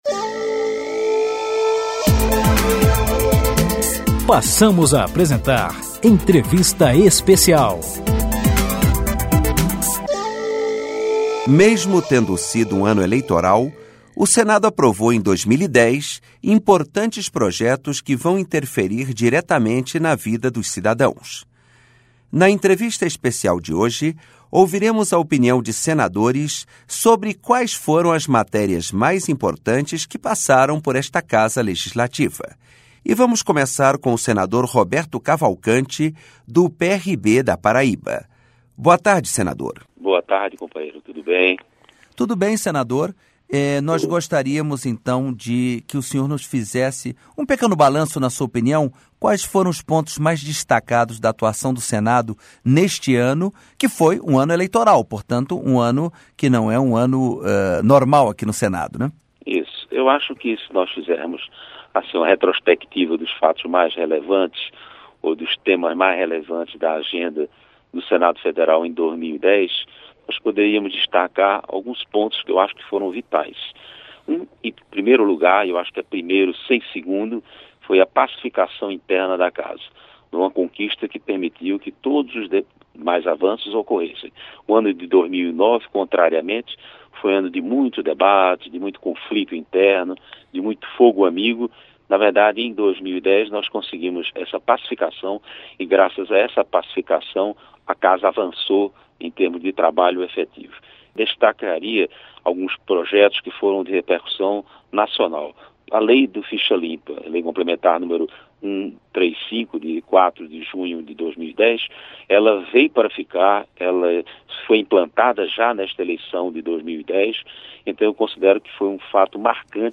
Entrevistas com os senadores Roberto Cavalcanti (PRB-PB), César Borges (PR-BA) e Valter Pereira (PMDB-MS).